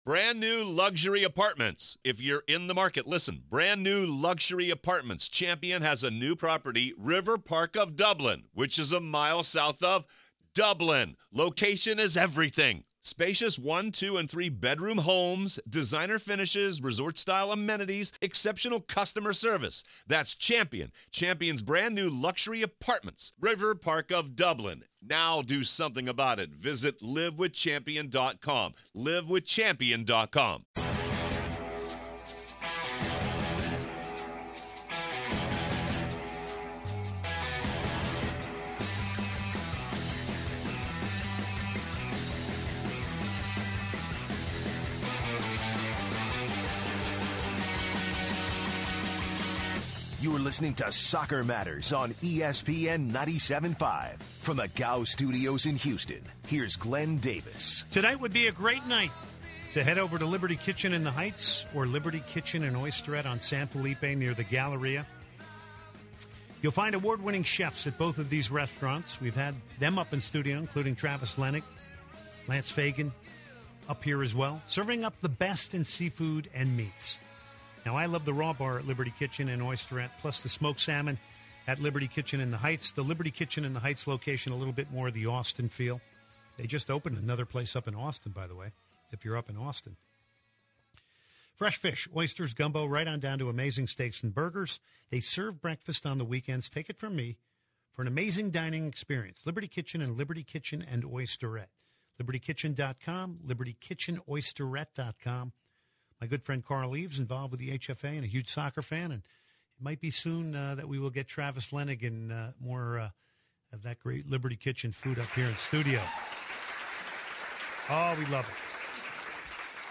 interviews Tony Meola, former US National Team Goalkeeper, and discusses the BPL and "MLS 2.0".